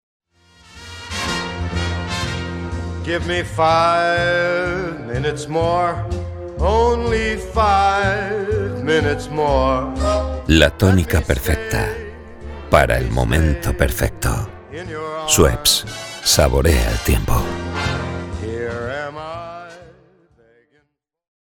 Volwassen, Warm, Speels, Stoer, Veelzijdig
Commercieel